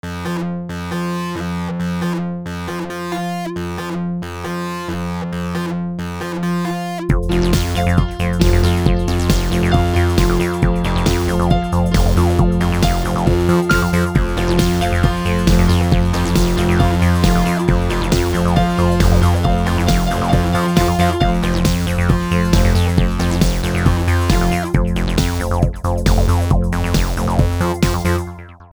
Toto jsem "složil": Jmenuje se to "What The F" a obsahuje to pouze tóny F. Ano, jsou to POUZE tóny F, které jsou různě vysoké (tedy různé násobky frekvence nejnižšího F krát 2 na n-tou) plus bicí (což je šum, který nemá definovatelnou konstantní frekvenci). Ta "různorodost" je dána tím, že různá F jsou v různém rytmu, různě hlasitá, s různými dozvuky a ozvěnami, a především  mají rozdílnou barvu zvuku - to znamená, že kmitání není sinusoida, nýbrž různé jiné složitější křivky, případně s proměnlivým tvarem v průběhu času.